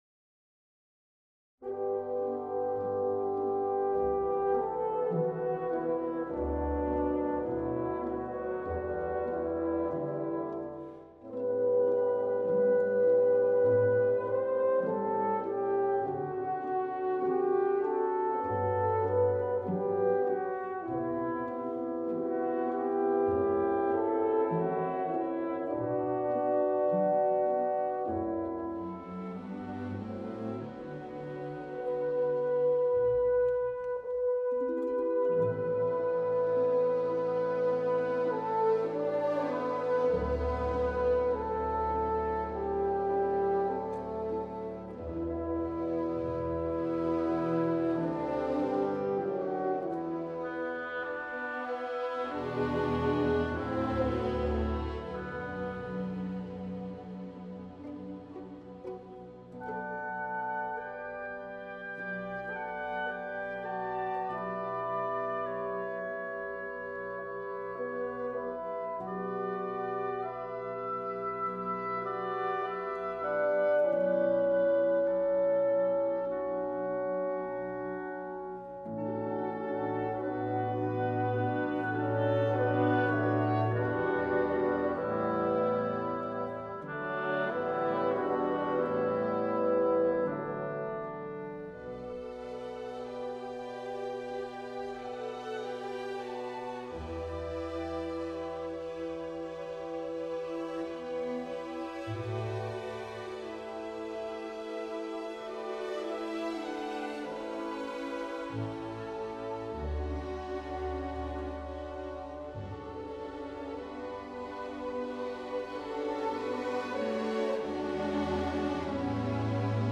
Maurice Ravel - Pavane pour une Infante défunte - Nahráno ve studiu ČRo v r. 2007